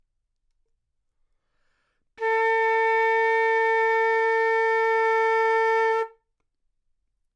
长笛单音（吹得不好） " 长笛 A4 坏动态
描述：在巴塞罗那Universitat Pompeu Fabra音乐技术集团的goodsounds.org项目的背景下录制。
Tag: 好声音 单注 多样本 A4 纽曼-U87 长笛